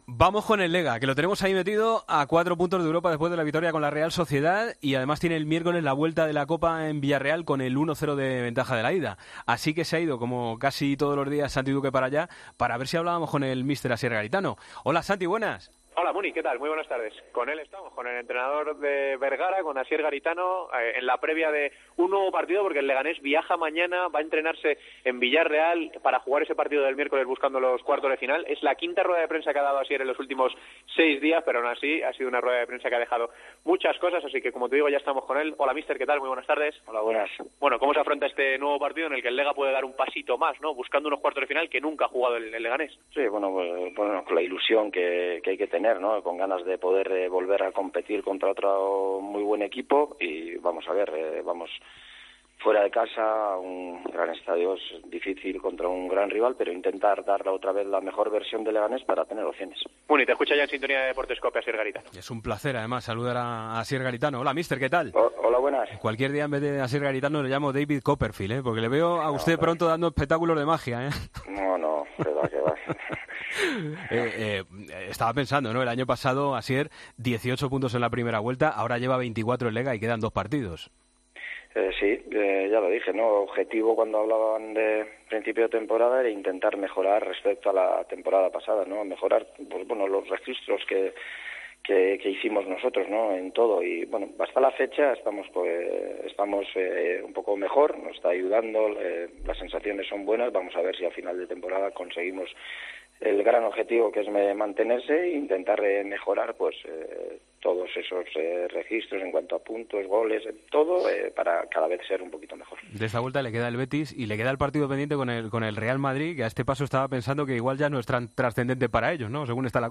Hablamos con el entrenador del Leganés: "Tenemos ganas e ilusión de pasar de ronda.